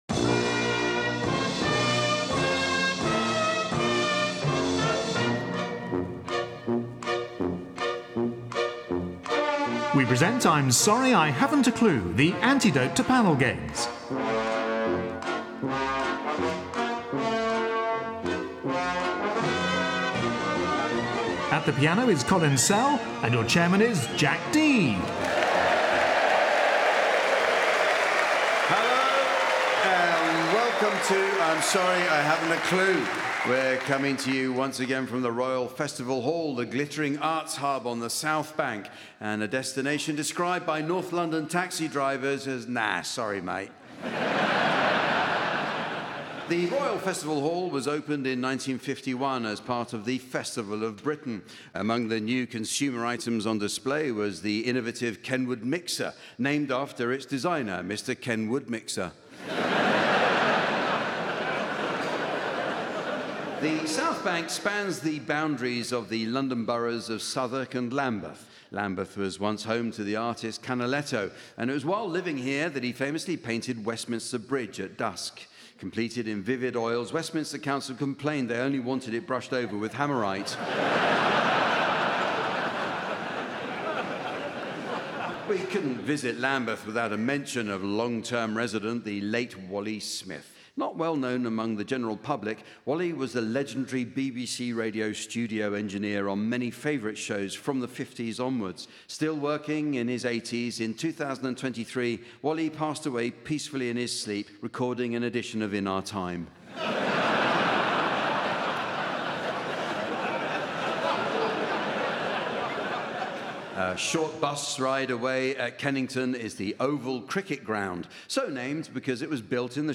The godfather of all panel shows returns to London's Royal Festival Hall. On the panel are Joe Lycett, Pippa Evans, Richard Coles and Tony Hawks with Jack Dee in the umpire's chair.